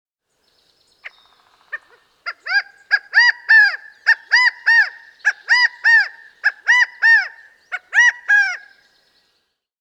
eNews: Who's Drumming? IDing Woodpeckers By Sound
Its loud call is a common sound of brushy areas in California and the Northwest.